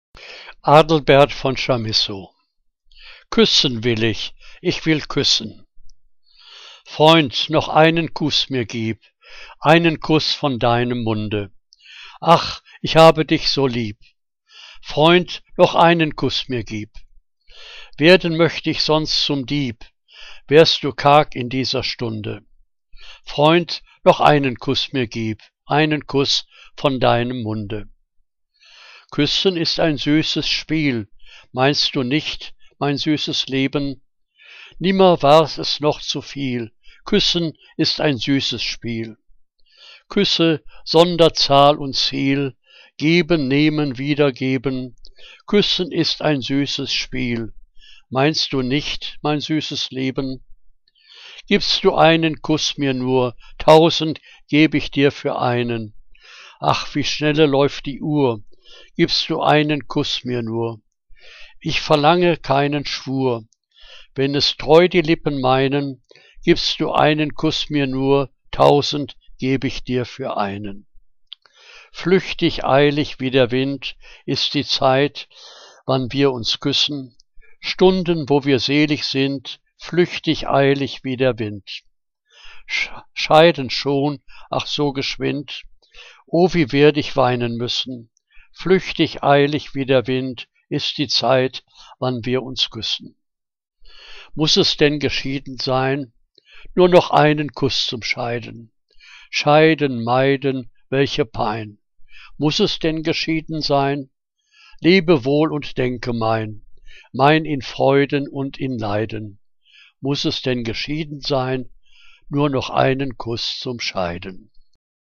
Liebeslyrik deutscher Dichter und Dichterinnen - gesprochen (Adelbert von Chamisso)